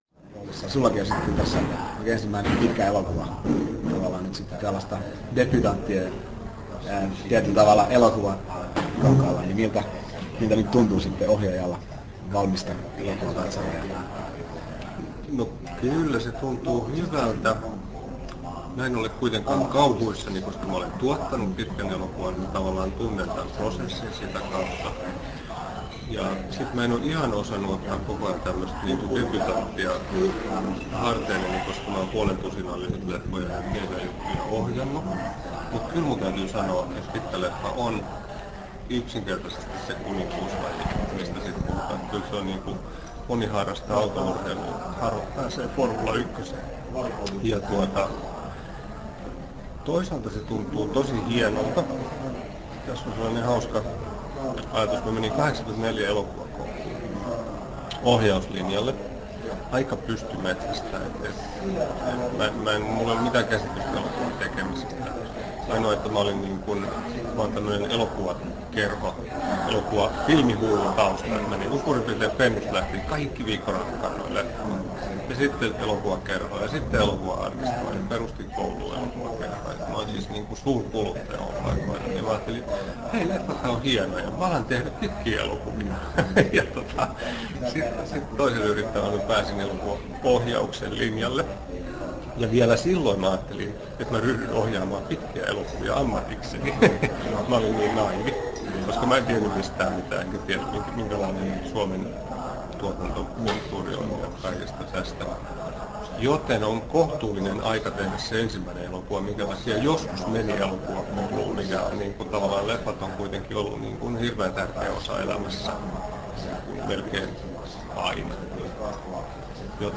Nauhoitettu Turussa 19.08.2002